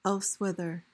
PRONUNCIATION:
(ELS-with-uhr, els-WITH-)